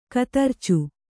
♪ katarcu